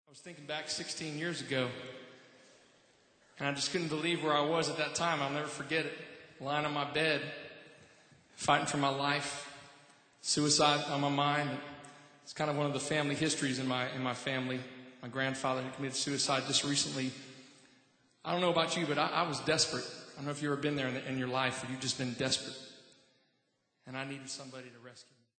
This passionate live recording
traditional & modern worship songs & hymns of faith